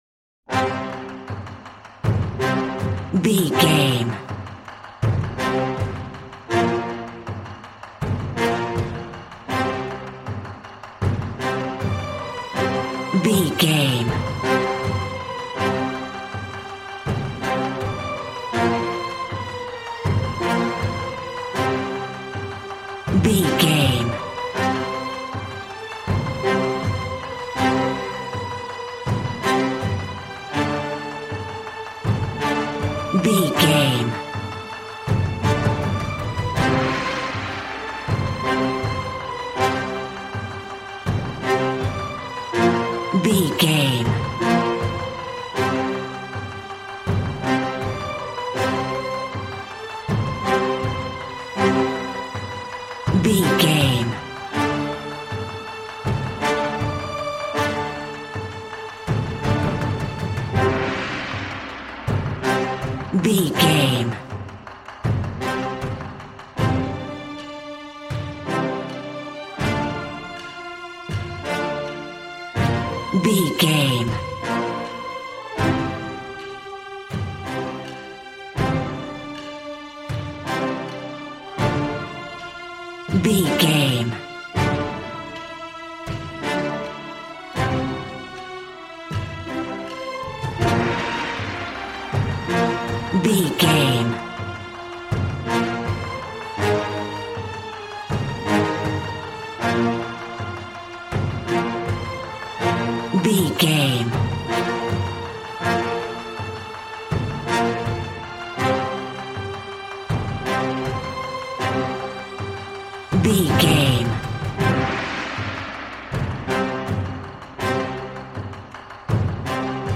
Action and Fantasy music for an epic dramatic world!
Ionian/Major
hard
groovy
drums
bass guitar
electric guitar